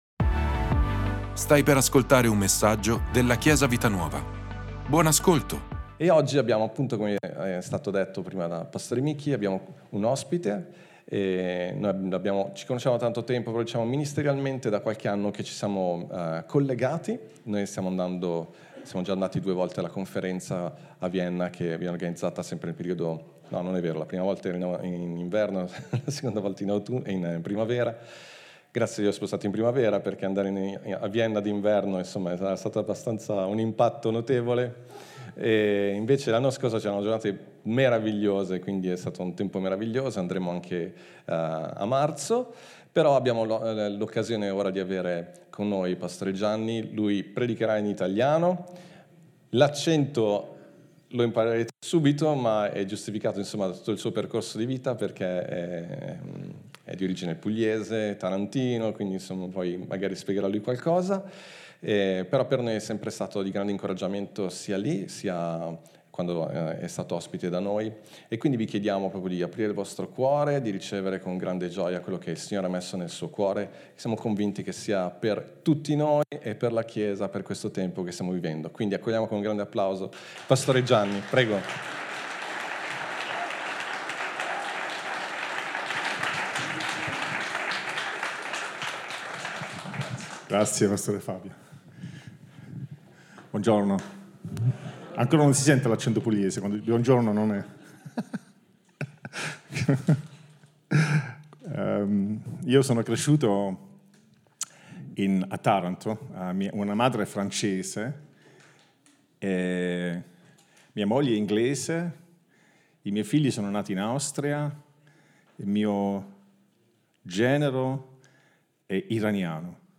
Ascolta la predicazione "04/26_Io ci sono " di Chiesa Vita Nuova.